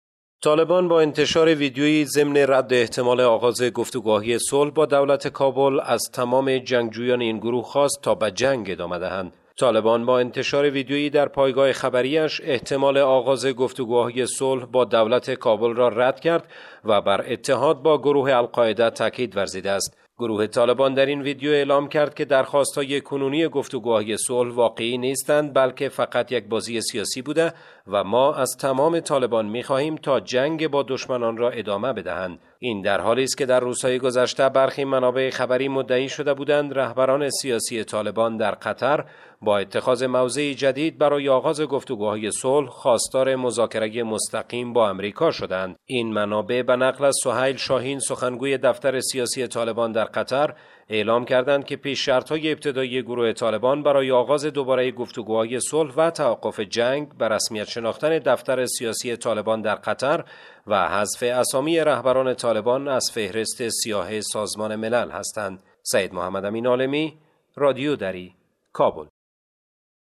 به گزارش خبرنگار رادیو دری، طالبان با انتشار ویدیوئی در پایگاه خبری اش احتمال آغاز گفت وگوهای صلح با دولت کابل را رد و بر اتحاد با گروه القاعده تأکید کرده است.